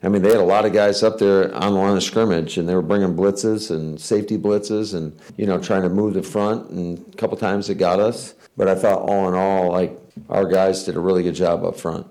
Packers coach Matt LaFleur talked about the win.